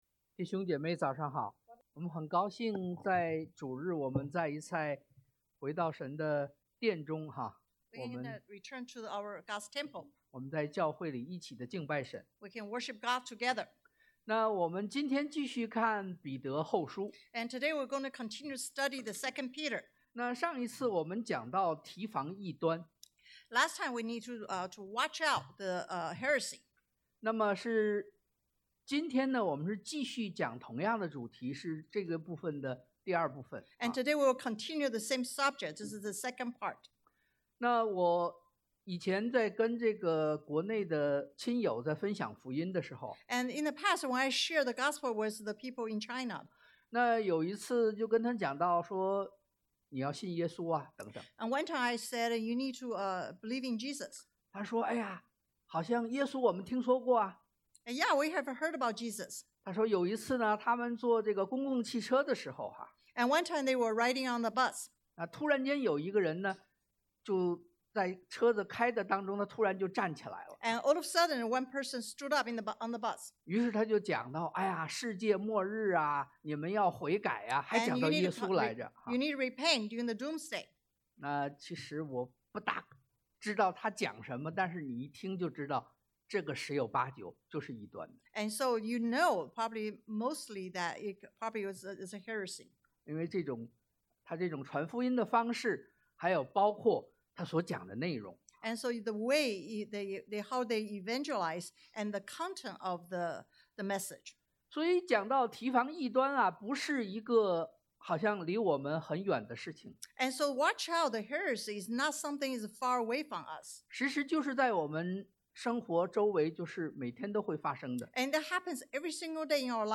Service Type: Sunday AM Don’t go astray from the truth 不要誤入歧途 Don’t overlook God’s grace 不要輕忽恩典 Be solid in Christ 主內堅固